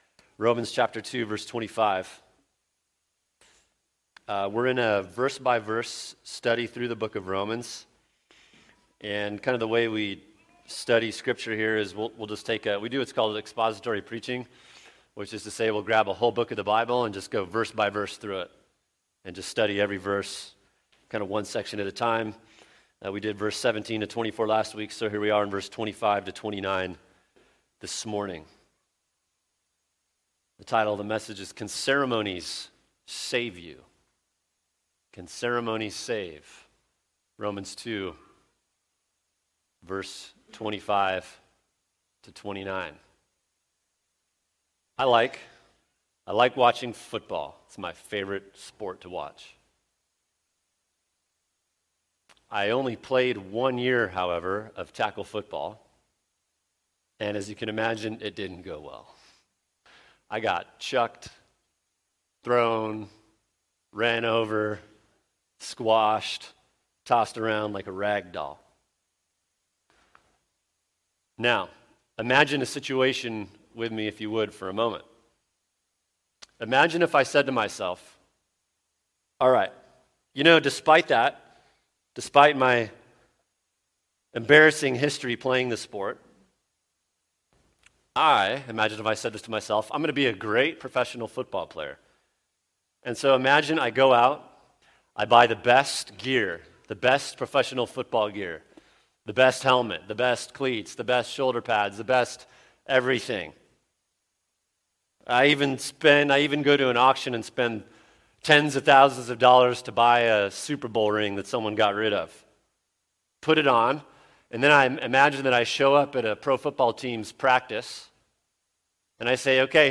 [sermon] Romans 2:25-29 Can Ceremonies Save You | Cornerstone Church - Jackson Hole